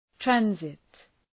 Προφορά
{‘trænzıt, ‘trænsıt}